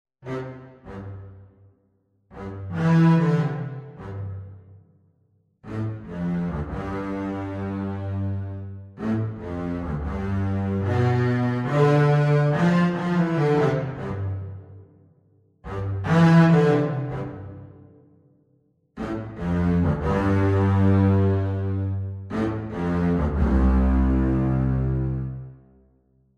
Two pieces for unaccompanied Double Bass
Double Bass Solo